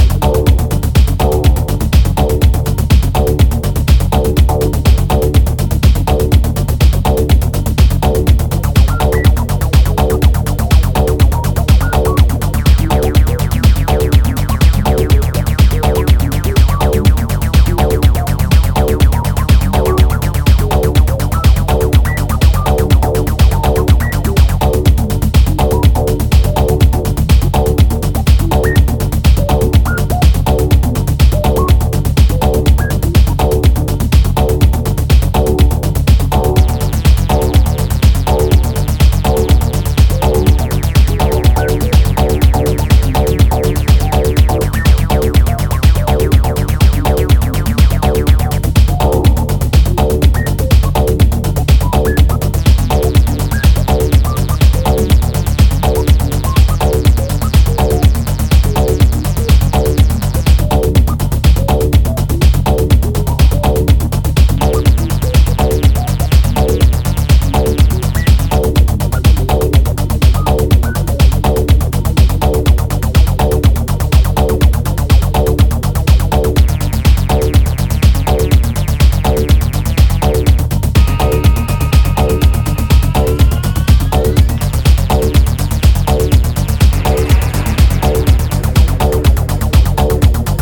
This hypnotic 13-minute beast of a track